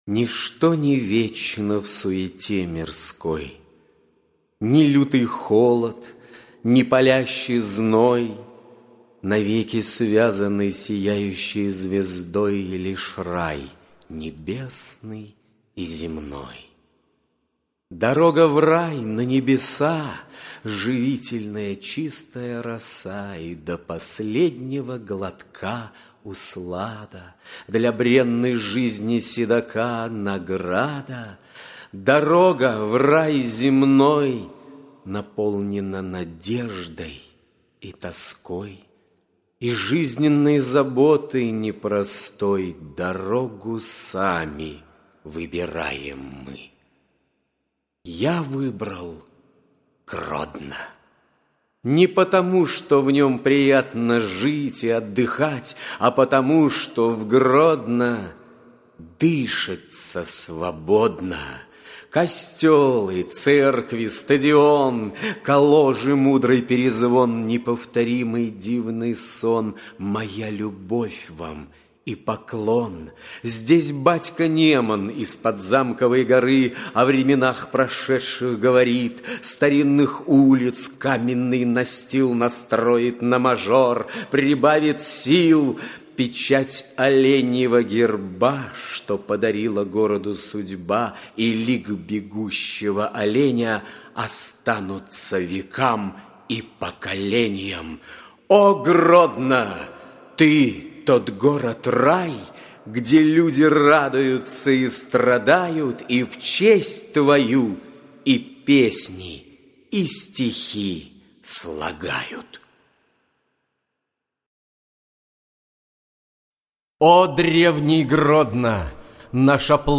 Аудиокнига Мосты надежды, веры и любви | Библиотека аудиокниг